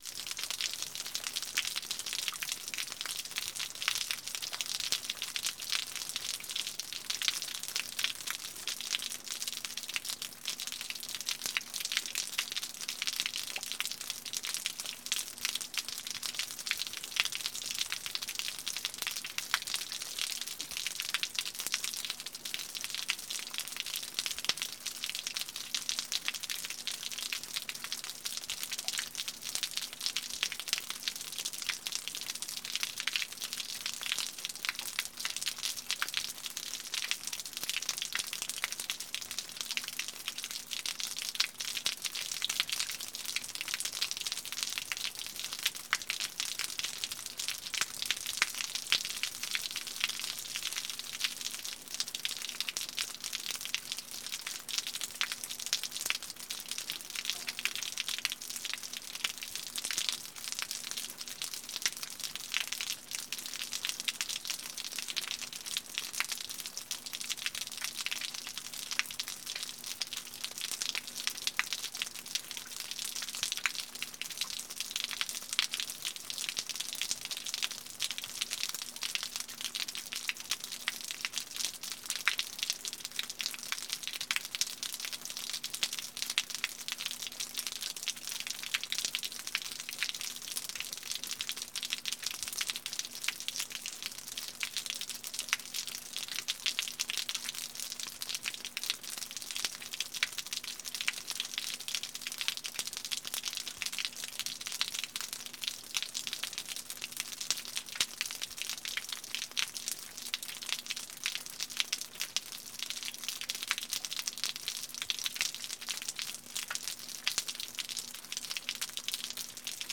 The sound of rain dripping from the roof - Eğitim Materyalleri - Slaytyerim Slaytlar